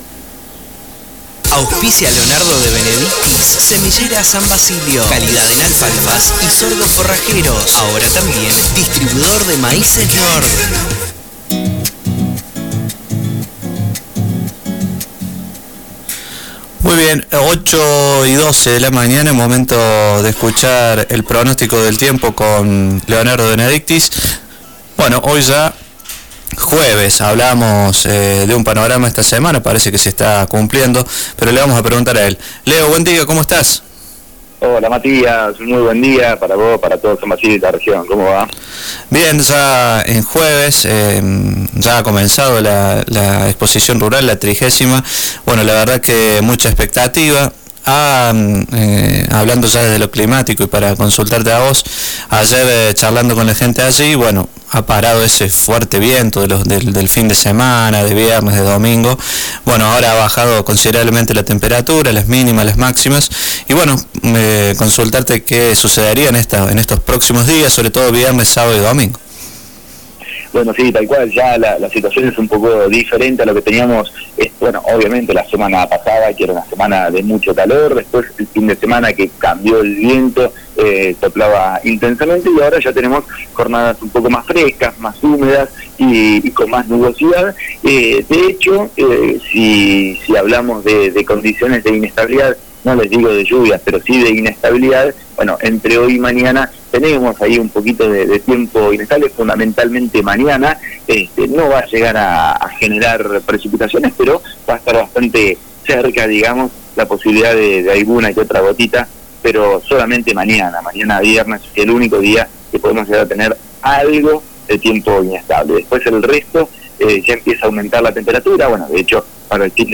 pronostico-jueves-3.mp3